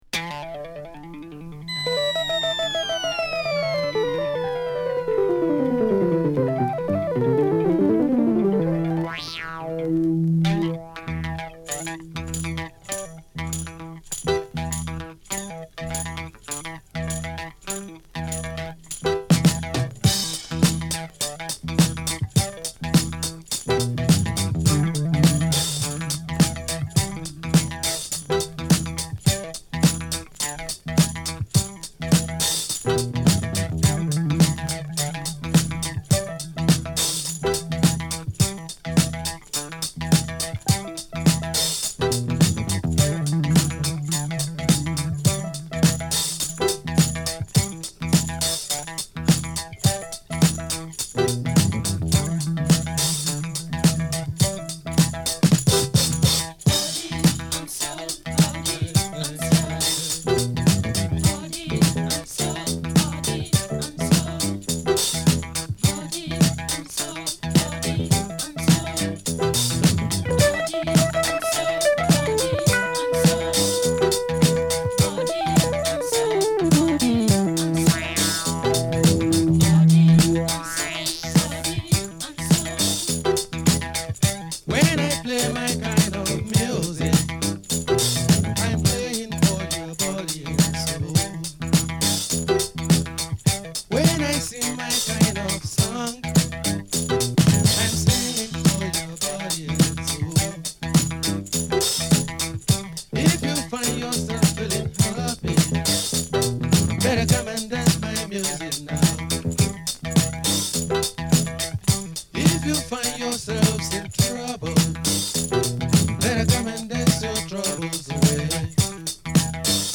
他では絶対に味わう事のできないアフロ・ディスコ～レアグルーヴの世界が楽しめるアルバム